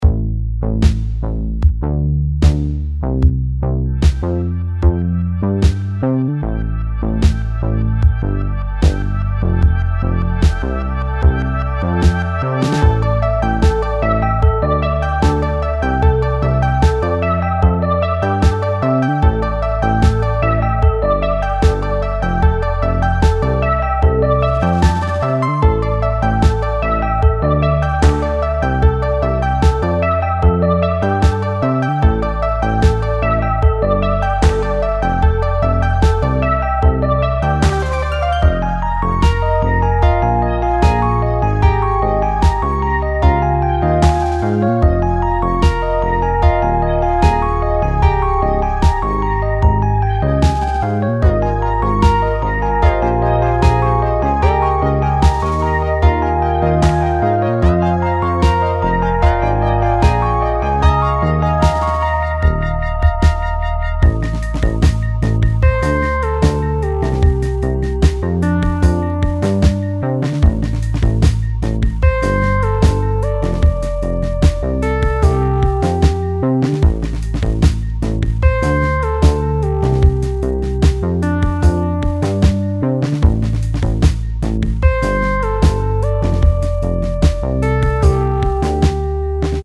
clicks.ogg